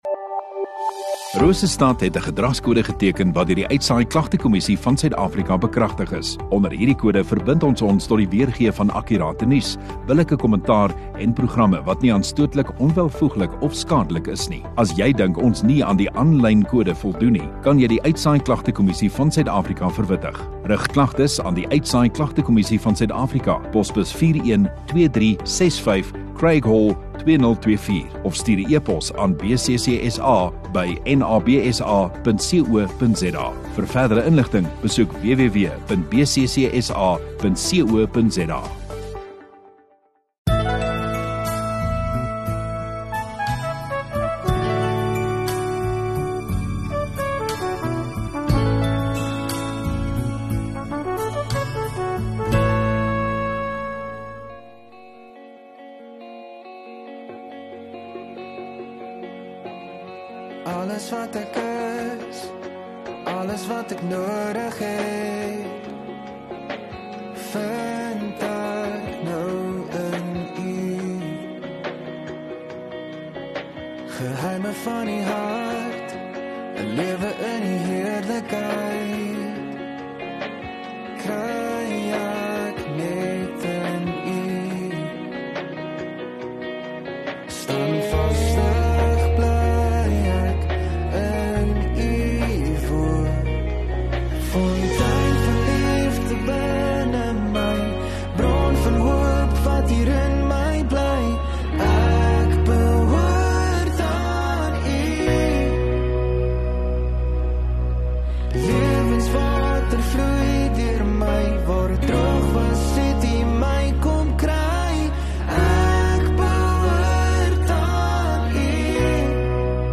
4 Jan Saterdag Oggenddiens